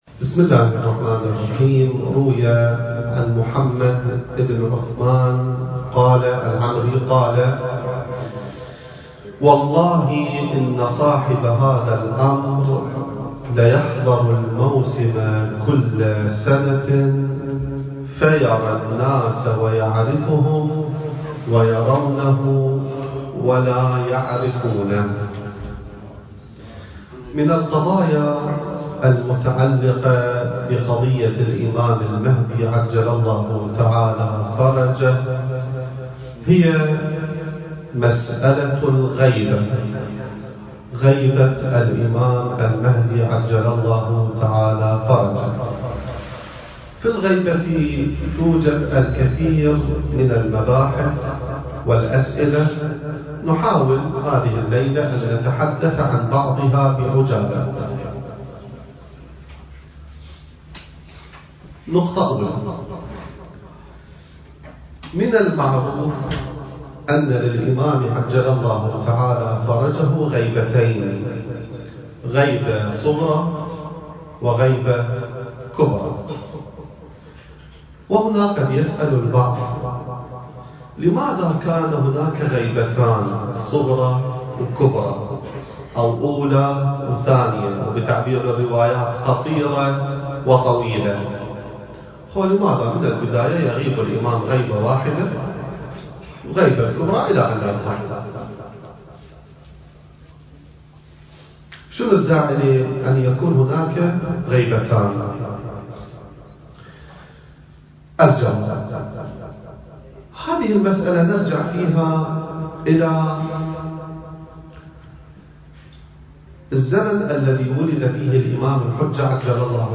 المكان: مؤسسة الإمام السجاد (عليه السلام) / استراليا التاريخ: 2019